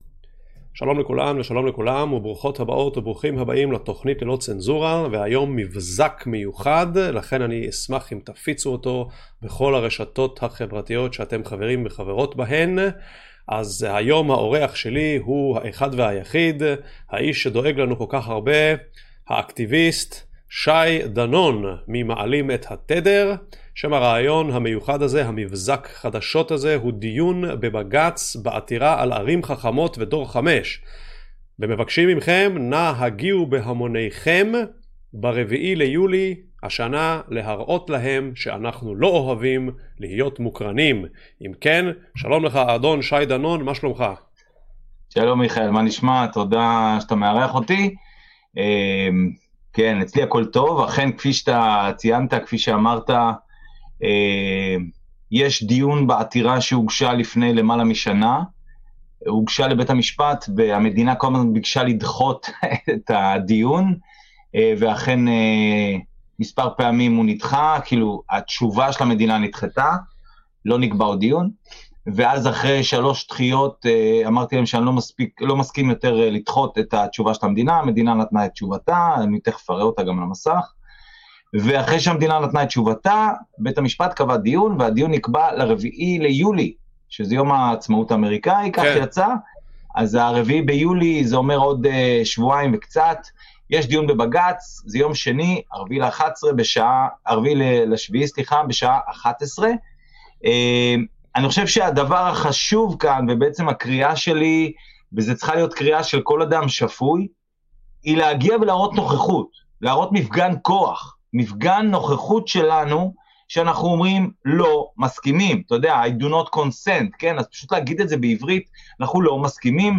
דיון בבגץ בעתירה על ערים חכמות ודור 5